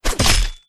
Heavy_Sword5.wav